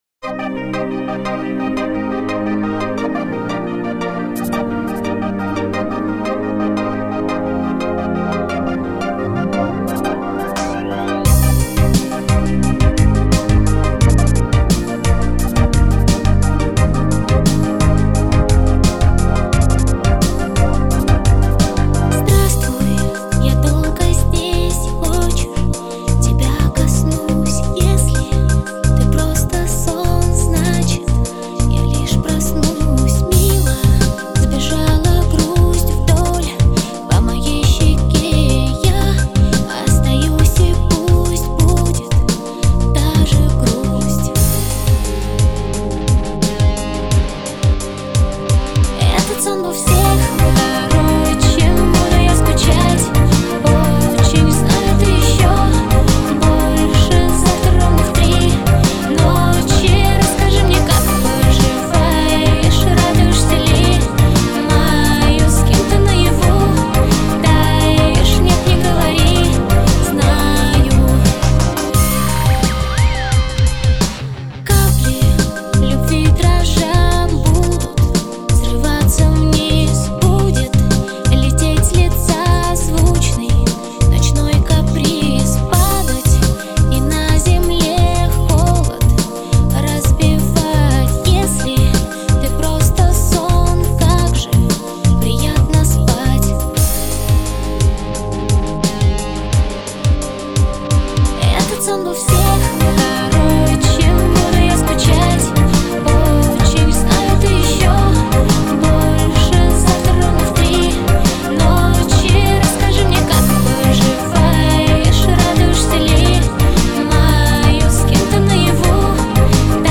Жанр: Поп Продолжительность: 00:47:21 Список композиций: